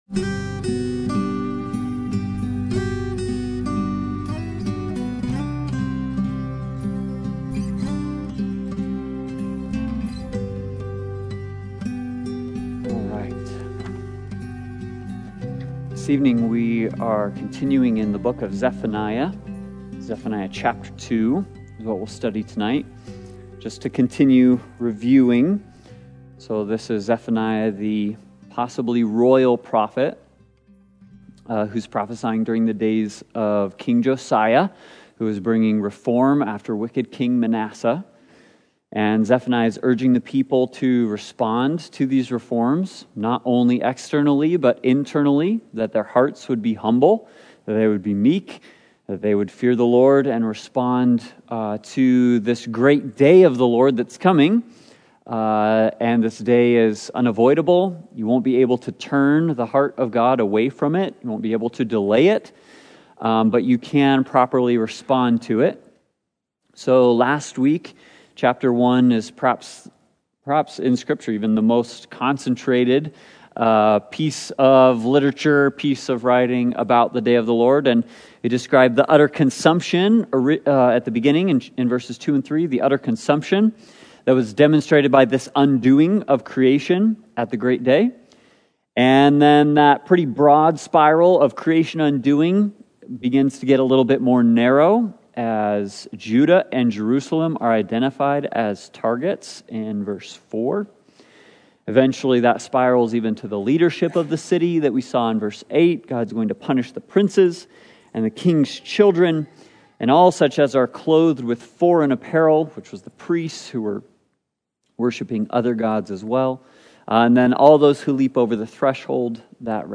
Service Type: Sunday Bible Study